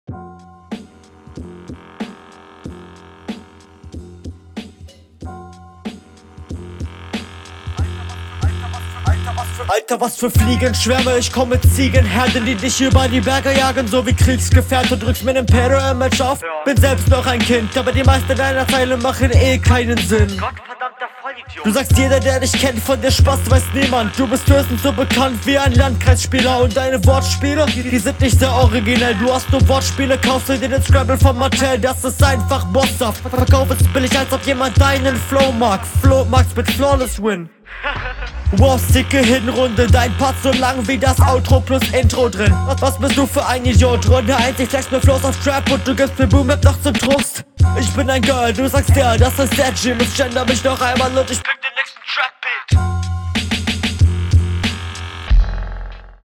Der Effekt in der Stimme gefällt mir diesmal gar nicht.